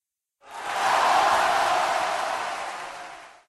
soccer_goal.ogg